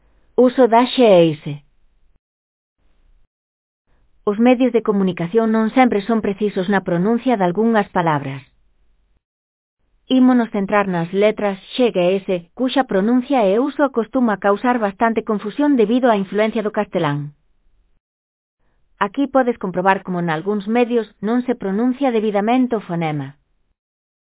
Aquí podes comprobar como nalgúns medios non se pronuncia debidamente o fonema /ʃ/ para a letra “x”, pois tende a confundirse co fonema /s/ da letra “s”.